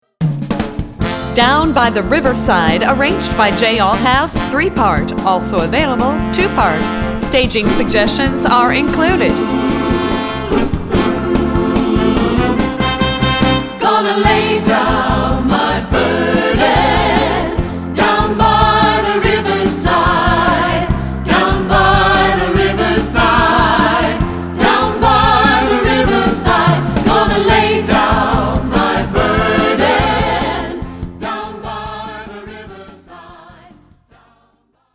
Composer: Spiritual
Voicing: 2-Part